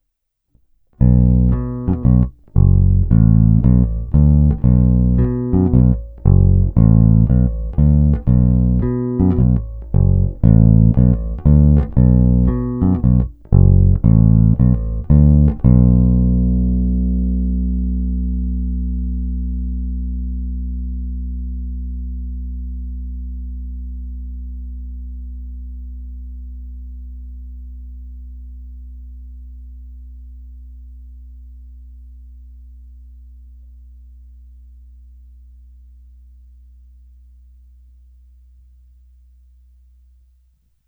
Zvuk je plný, pevný, bohatý středobasový základ doplňuje slušná porce kousavosti.
Není-li uvedeno jinak, následující nahrávky jsou provedeny rovnou do zvukové karty, jen normalizovány, jinak ponechány bez úprav. Tónová clona vždy plně otevřená.
Hra nad snímačem